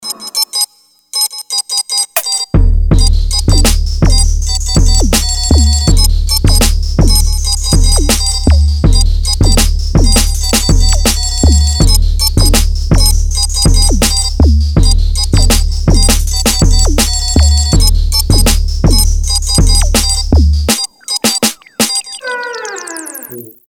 Electronic
без слов
glitch hop
биты
Прикольный биток из Тик Тока